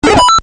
お聞きの通り、SE丸パクリです。
このBGM・SEが使用されているタイトルをお答えください。